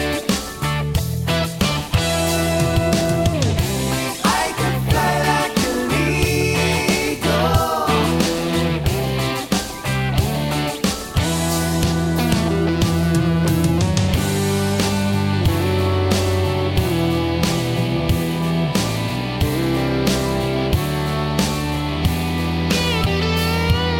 Cut Down Country (Male) 3:00 Buy £1.50